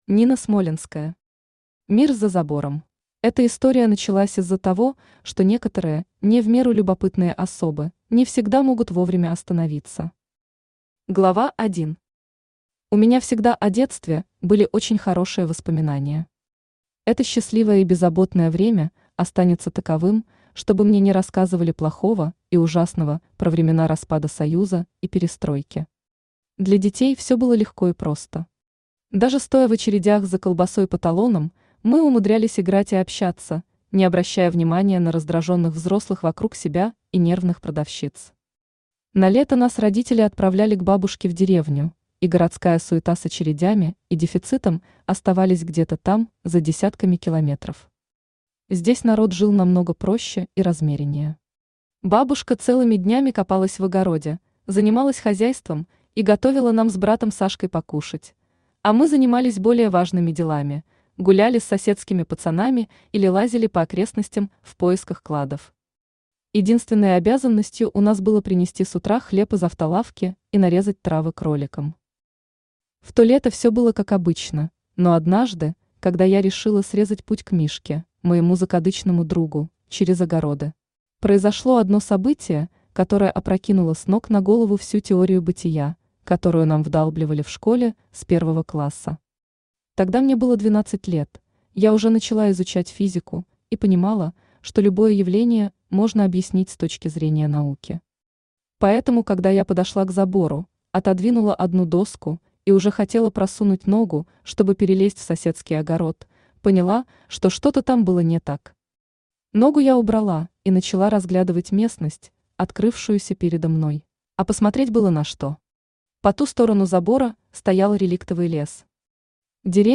Aудиокнига Мир за забором Автор Нина Смолянская Читает аудиокнигу Авточтец ЛитРес.